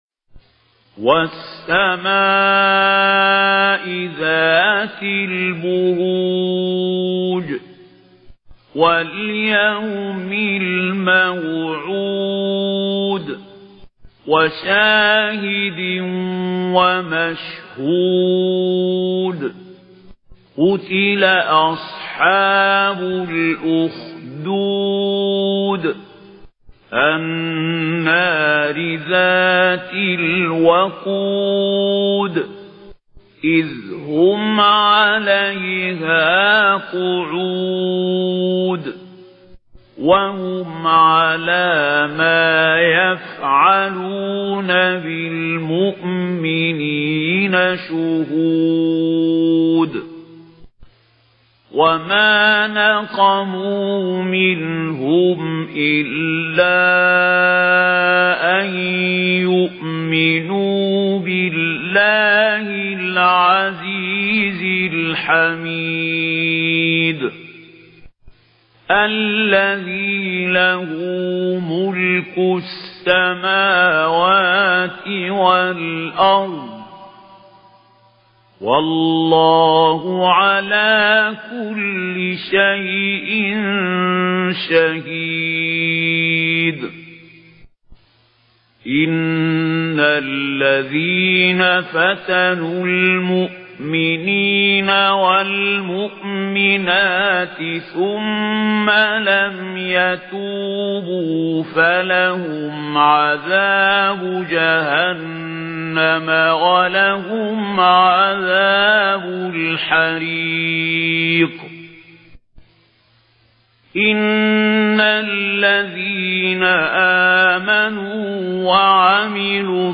Récitation par Mahmoud Khalil Al Hussary